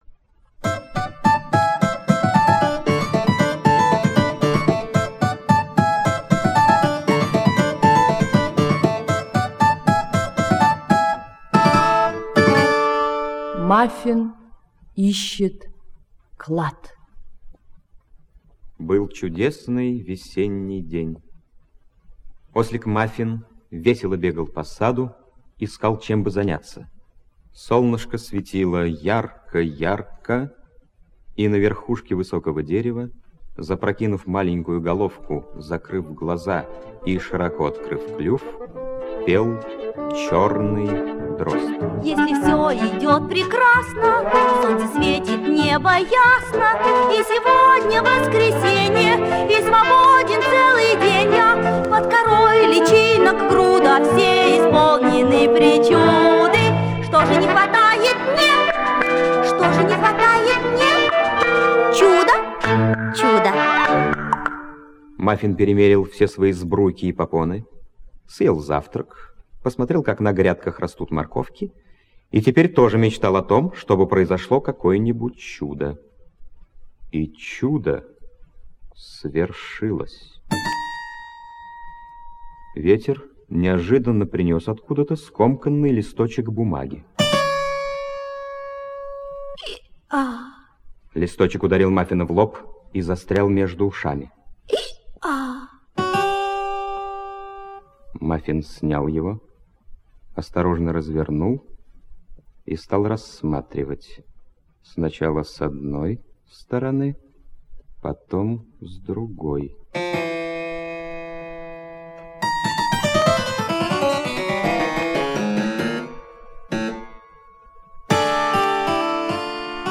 Мафин ищет клад — аудиосказка Хогарт - слушать онлайн